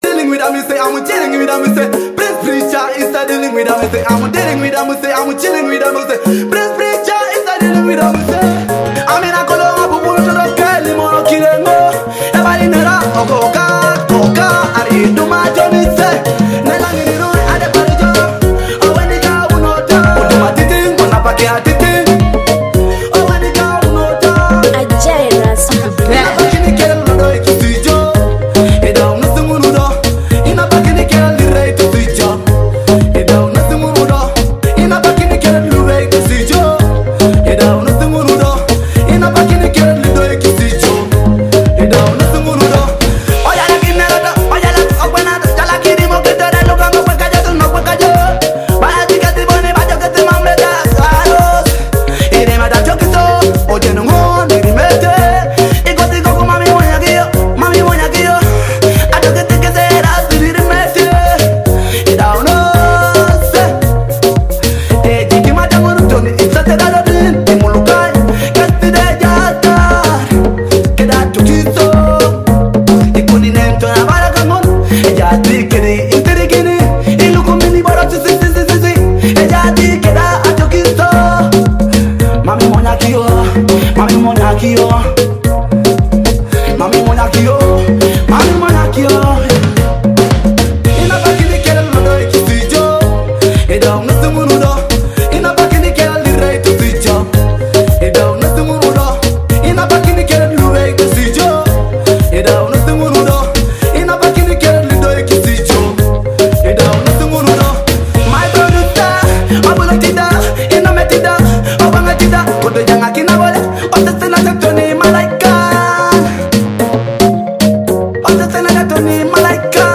Listen to authentic Teso music online!